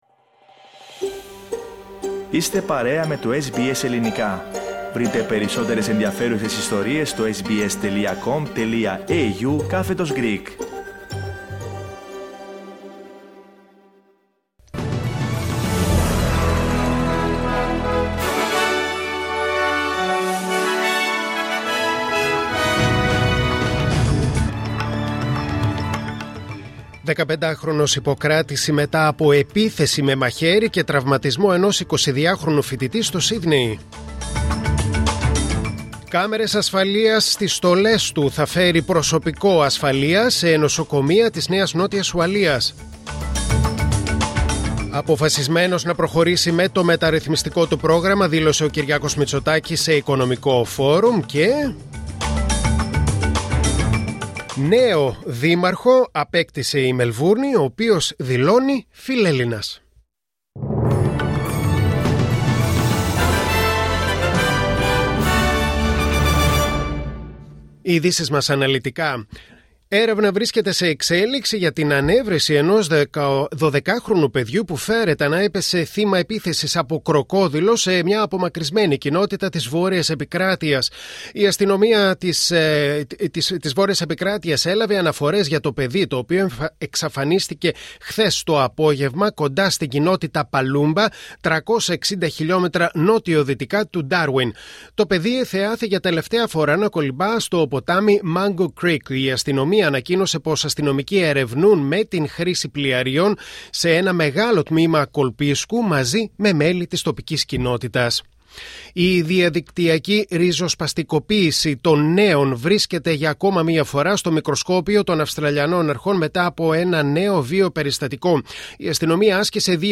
Δελτίο Ειδήσεων Τετάρτη 03 Ιουλίου 2024